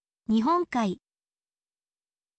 nihon kai